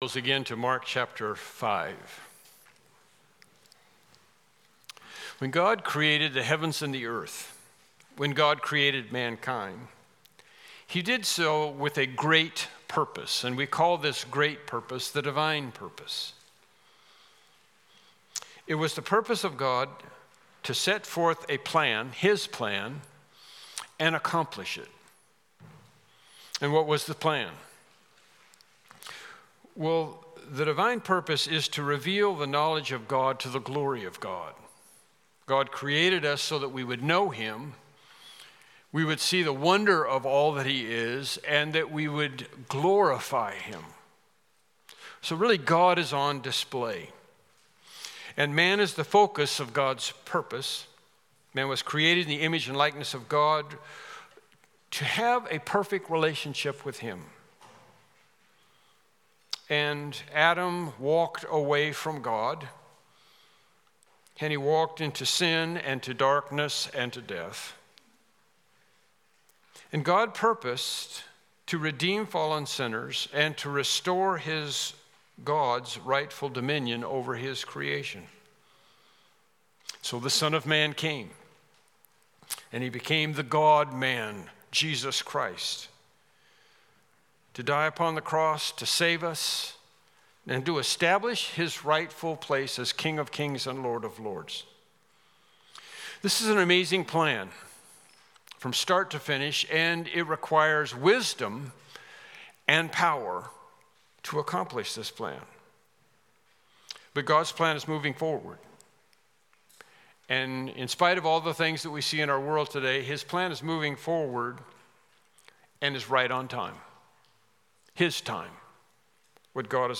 Passage: Mark 5:14-20 Service Type: Morning Worship Service